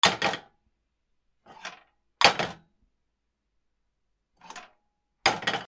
コンロになべを置く